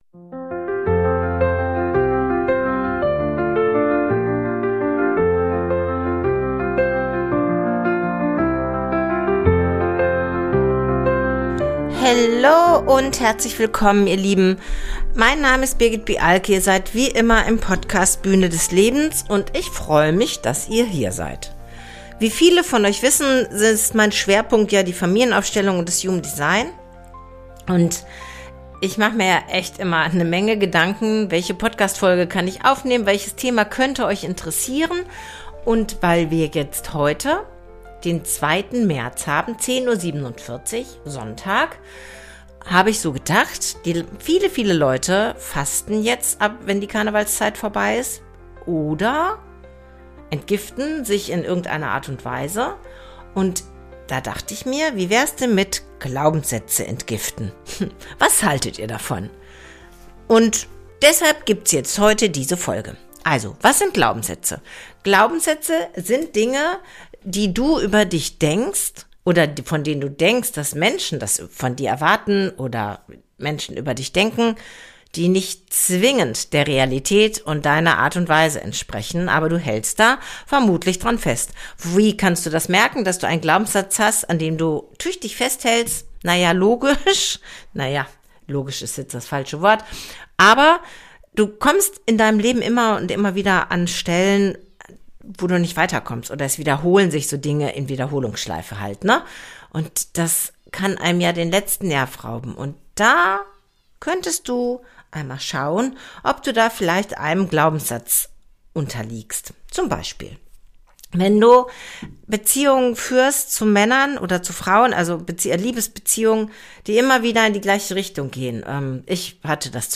Heute gibt es den Podcast und im Anschluss die Mediation